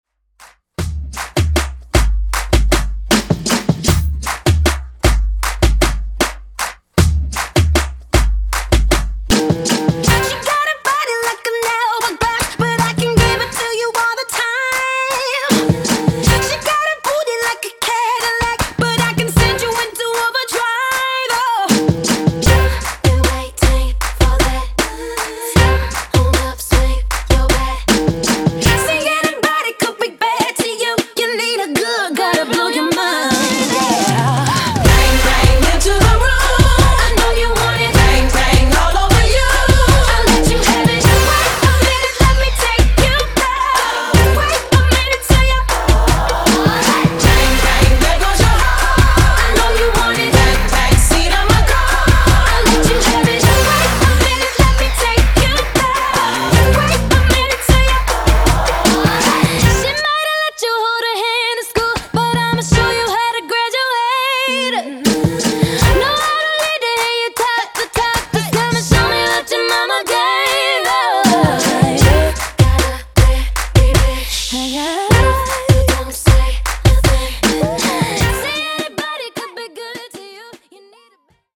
Genres: 80's , RE-DRUM
Clean BPM: 125 Time